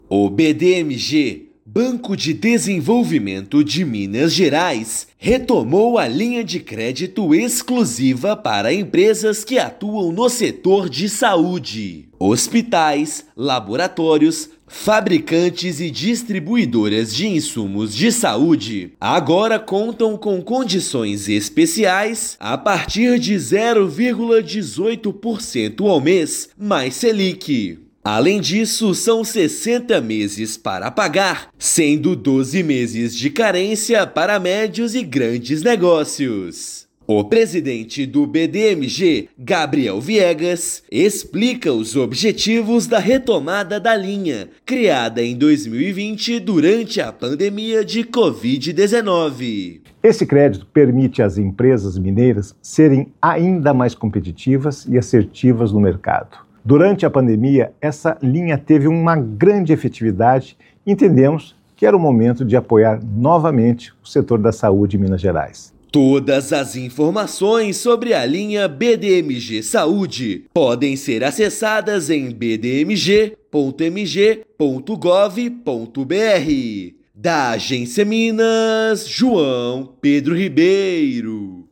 Banco retoma linha BDMG Saúde que oferta capital de giro em condições especiais para médias e grandes empresas investirem neste segmento. Ouça matéria de rádio.